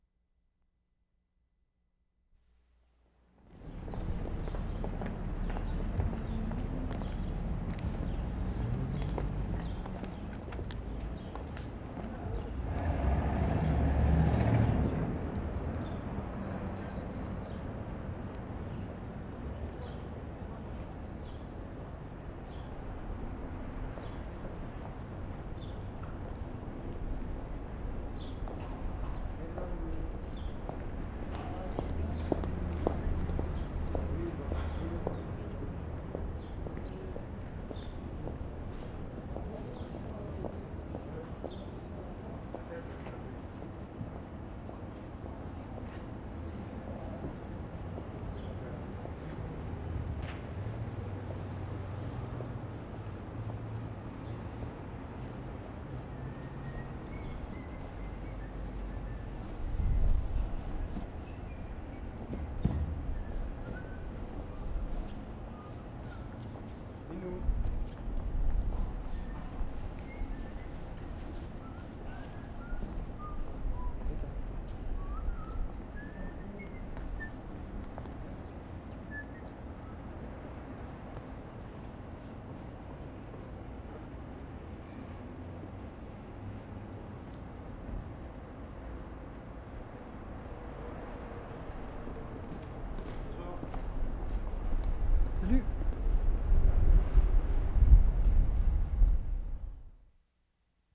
QUEBEC CITY, QUEBEC Oct. 27 1973
SMALL SIDE STREET, AMBIENCE AND RUG CLEANING 1'35"
4. Rug cleaning only faintly audible as a swishing sound. Otherwise lots of footsteps, birds. Somebody whistling (1'00"), somebody saying "salut" (1'25"), traffic in distance.